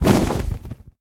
Minecraft Version Minecraft Version 1.21.5 Latest Release | Latest Snapshot 1.21.5 / assets / minecraft / sounds / mob / enderdragon / wings6.ogg Compare With Compare With Latest Release | Latest Snapshot
wings6.ogg